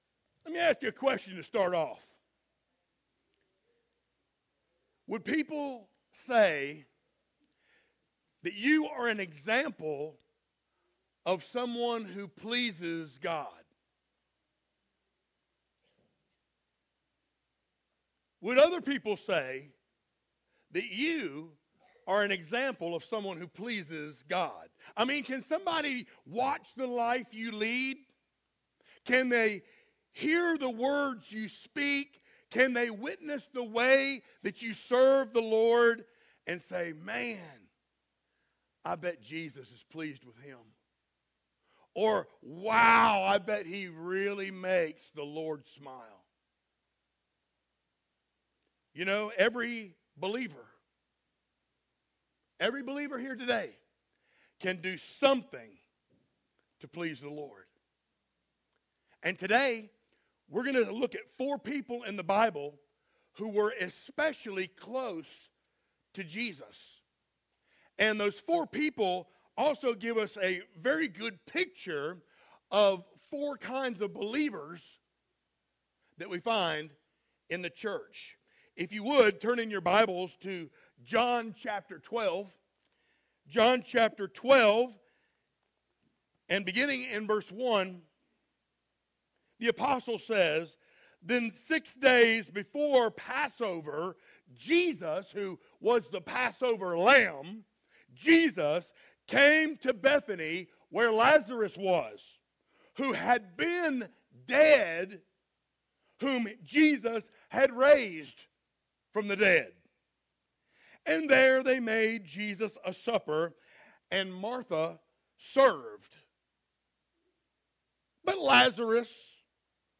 Series: sermons
John 12:1-11 Service Type: Sunday Morning Download Files Notes « Failing Forward God